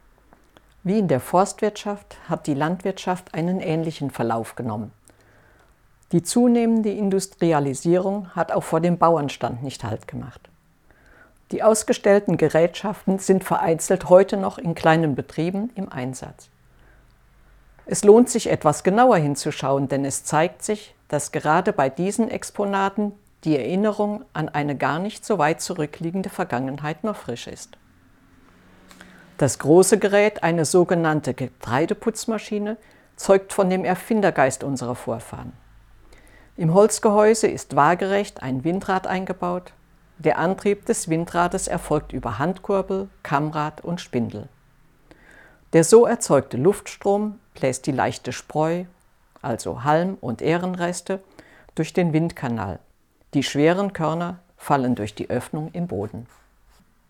Erleben Sie einen interessanten Rundgang durch unser Heimatmuseum "Altes Rathaus" in Loßburg und lassen Sie sich mit unserem Audioguide durch Raum und Zeit begleiten.